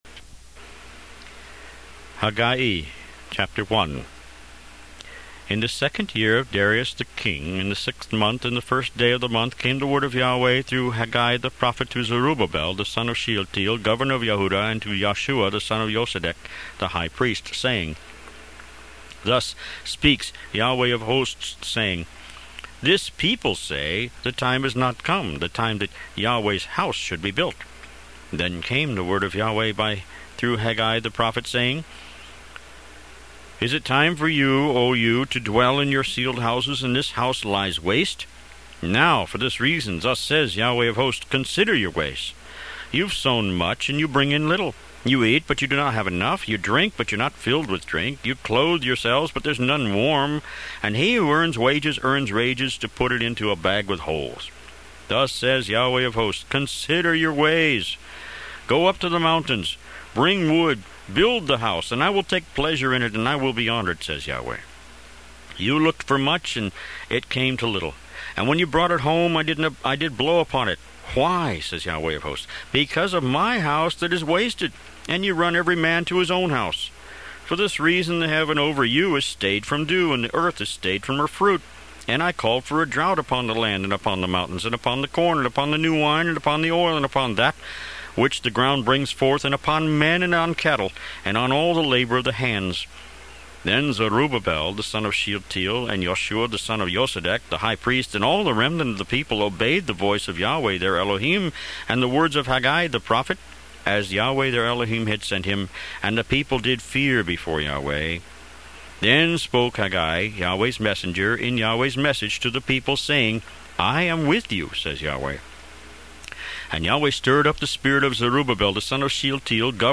Root > BOOKS > Biblical (Books) > Audio Bibles > Tanakh - Jewish Bible - Audiobook > 37 Haggai File name Size Last changed .. 01haggai.mp3 2.1 MB 27.02.11 12:16:02 02haggai.mp3 3.02 MB 27.02.11 12:16:02